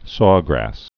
(sôgrăs)